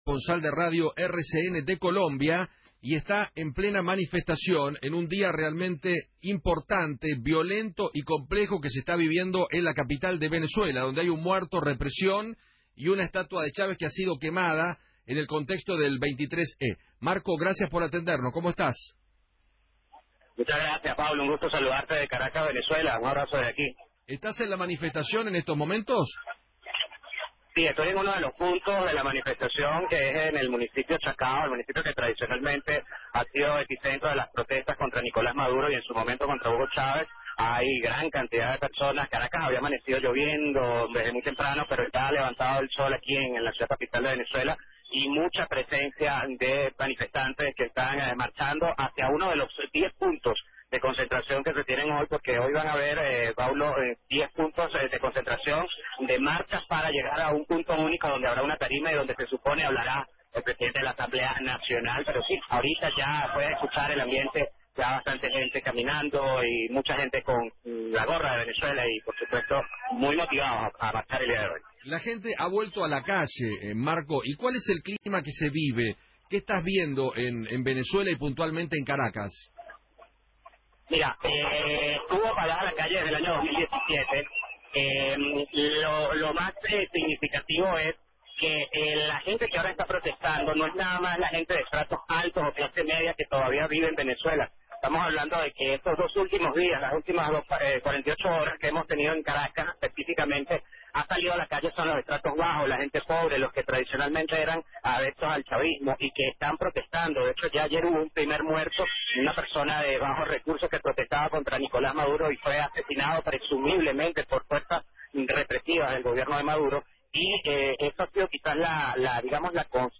periodista venezolano desde Caracas